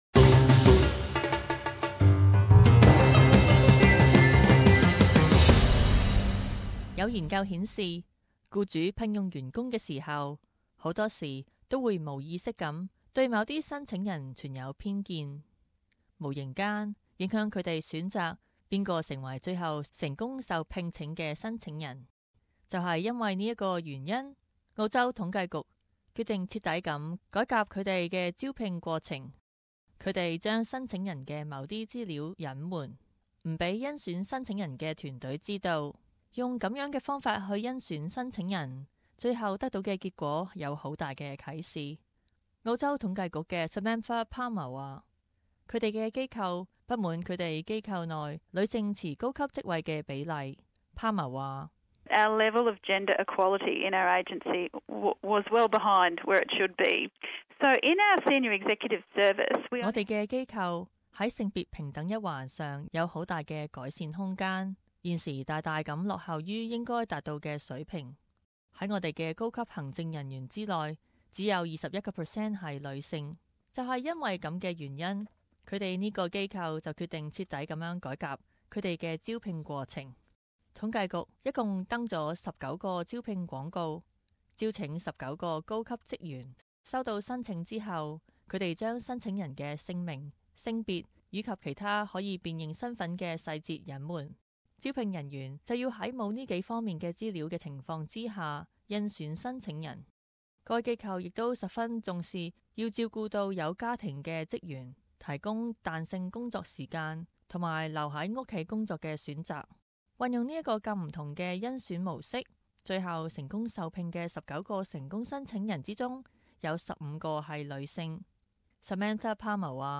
事时报道: 隐暪申请人部分资料助招聘更公平